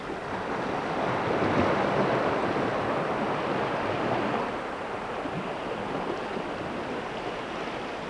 oceanwaves.wav